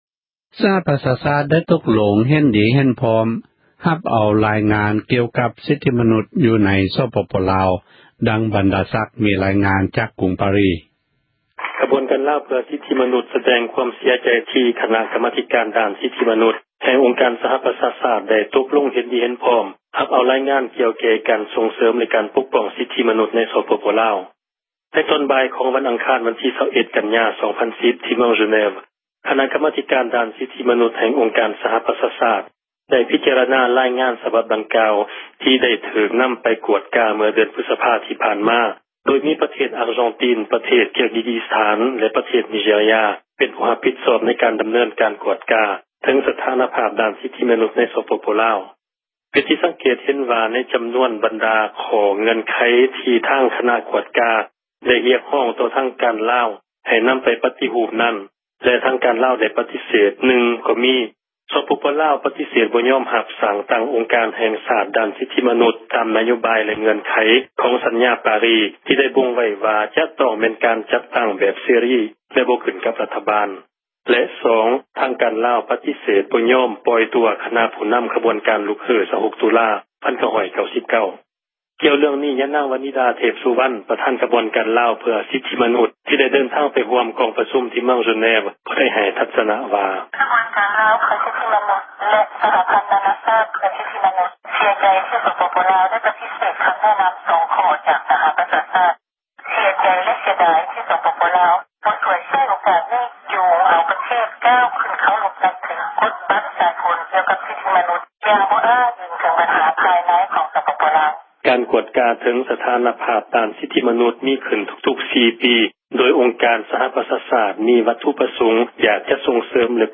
ຣາຍງານກ່ຽວກັບ ສິທທິມະນຸສໃນລາວ ຖືກຮັບຮູ້ – ຂ່າວລາວ ວິທຍຸເອເຊັຽເສຣີ ພາສາລາວ